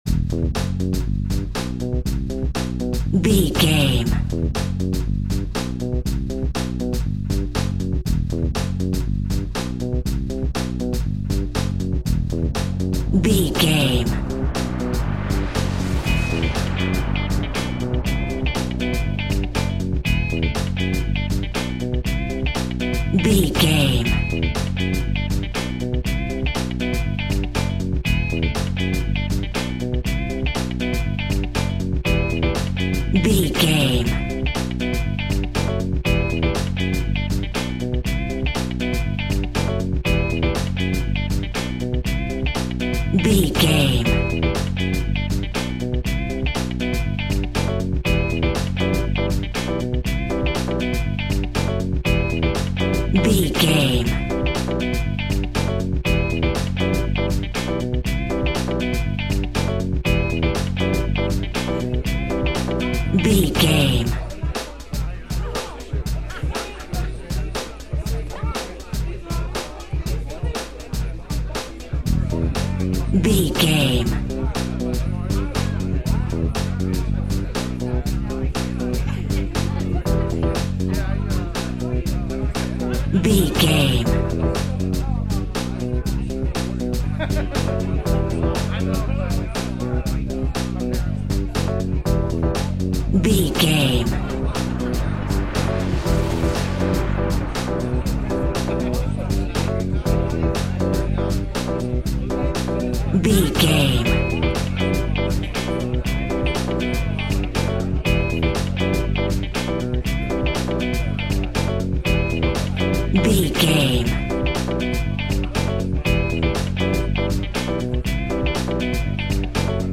In-crescendo
Aeolian/Minor
hip hop
hip hop instrumentals
downtempo
synth lead
synth bass
synth drums
turntables